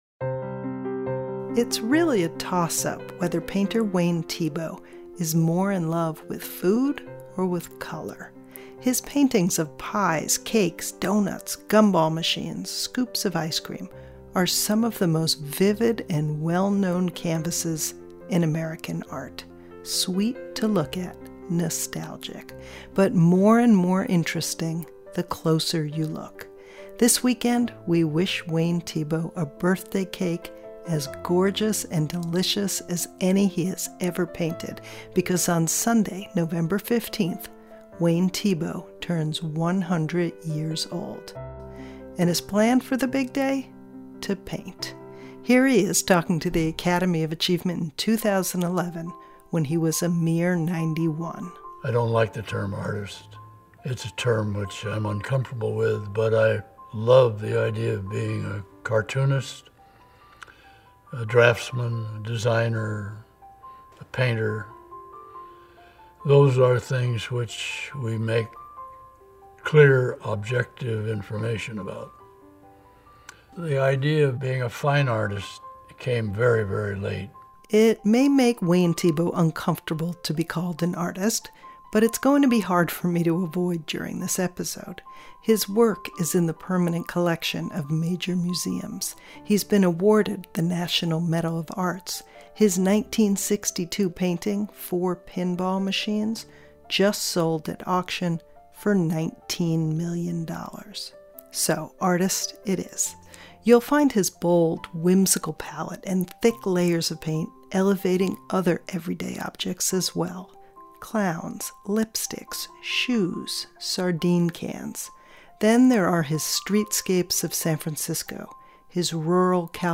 In celebration of painter Wayne Thiebaud's 100th birthday, we feature a conversation with the artist and with one of his most renowned students, Fritz Scholder.